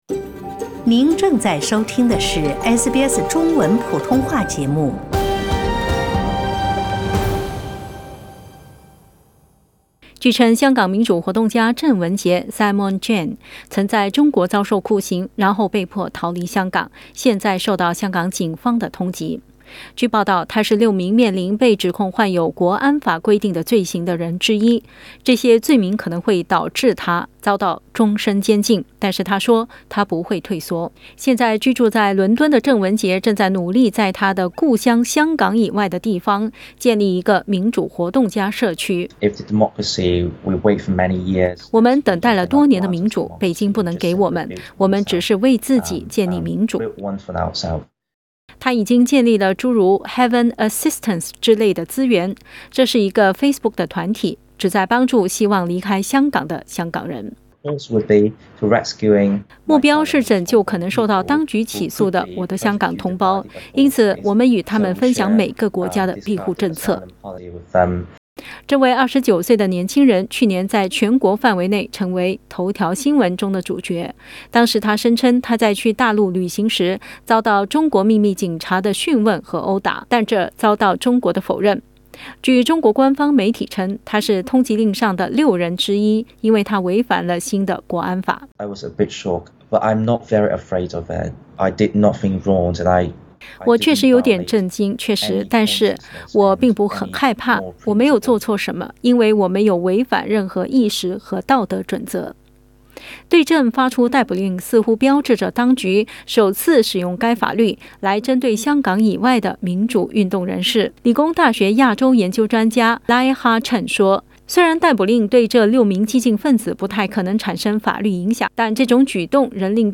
Activist Simon Cheng, speaking to SBS from London via video call Source: SBS